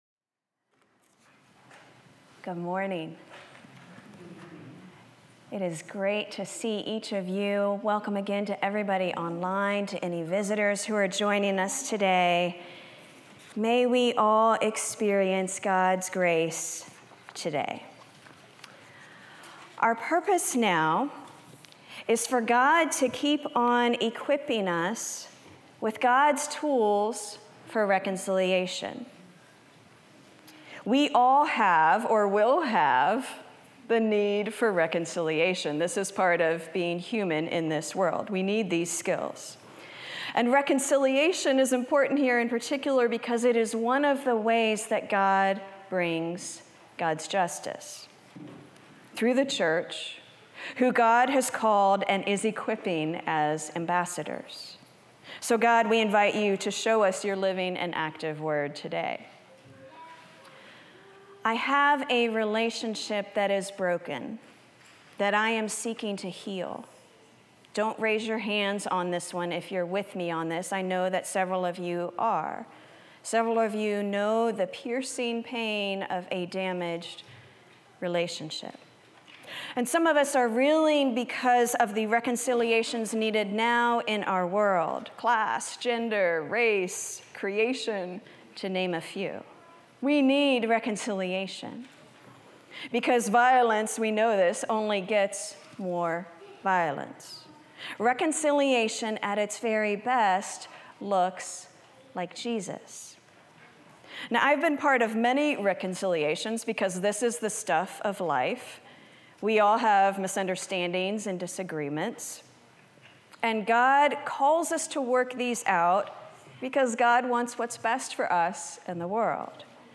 2 Corinthians 5:16-21 Order of worship/bulletin Youtube video recording Sermon audio recording.